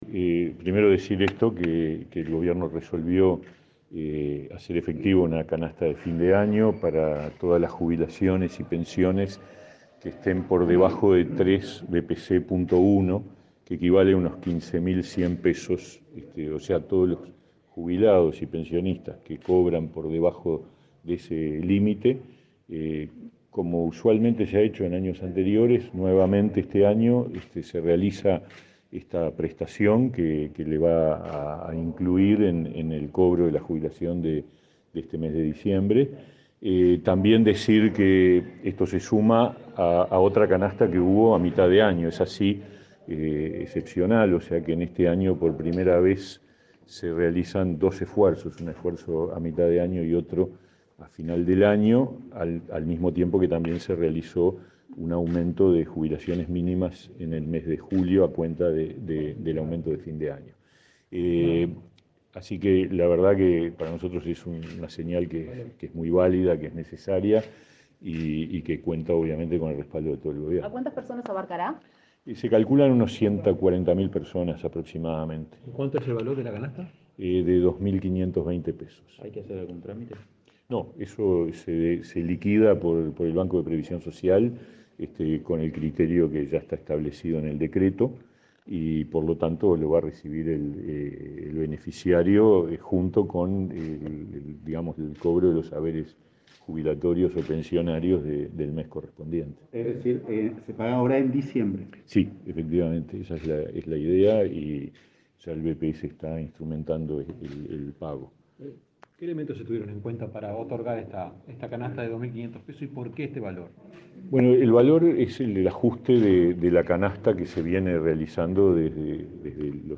Declaraciones a la prensa del ministro de Trabajo, Pablo Mieres